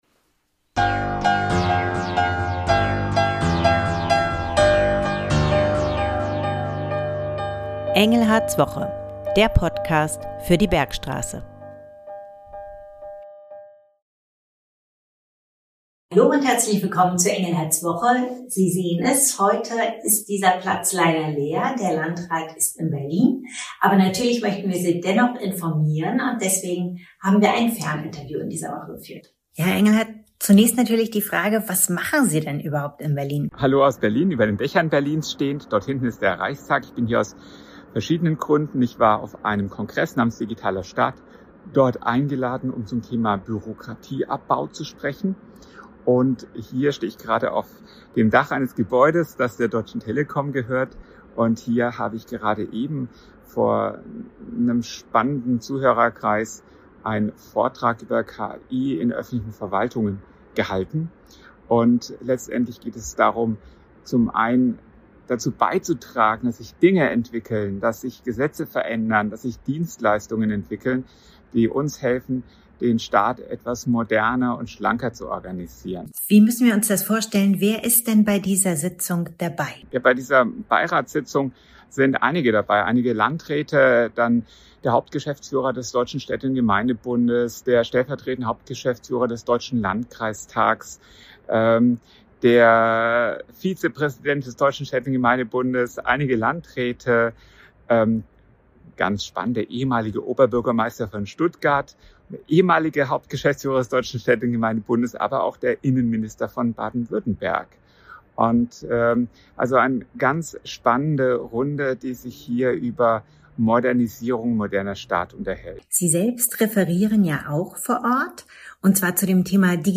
Schwerpunkte: Ferninterview mit Land Christian Engelhardt